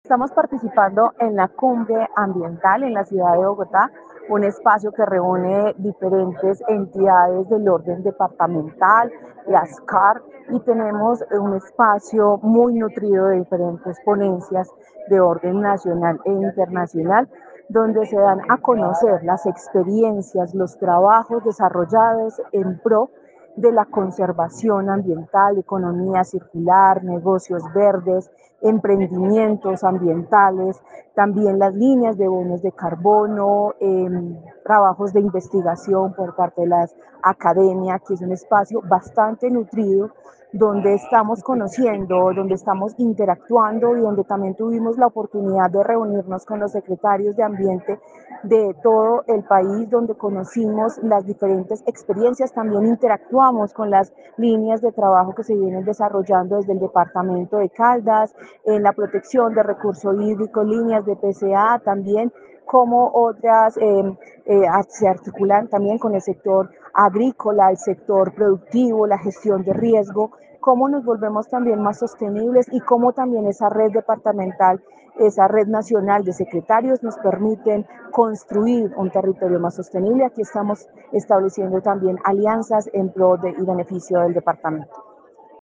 Paola Andrea Loaiza Cruz, secretaria de Medio Ambiente de Caldas.